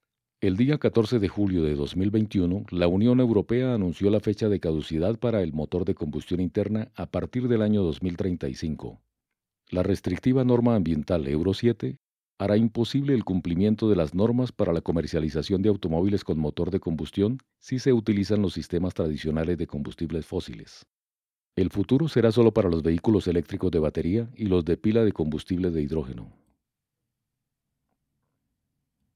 locutor profesional, documentales, actor de voz, comerciales e institucionales
Sprechprobe: Industrie (Muttersprache):